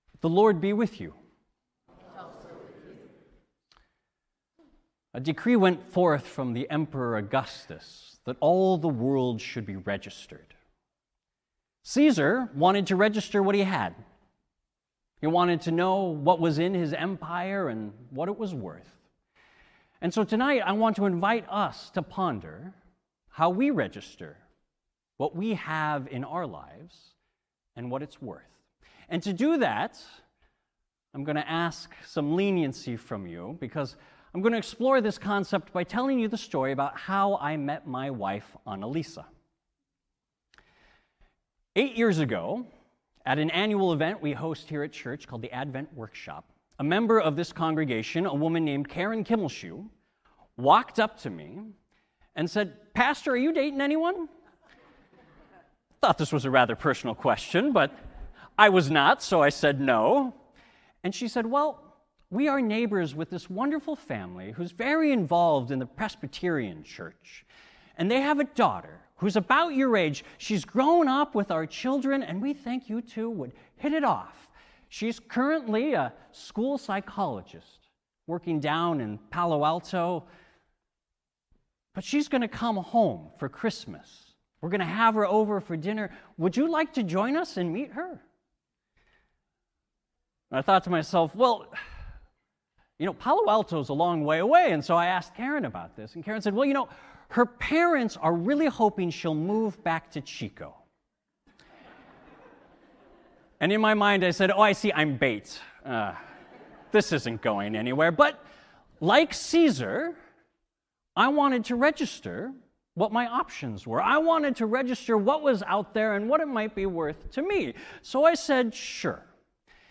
Faith Lutheran Church Sermons